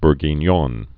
(brgēn-yôn, -yôɴ)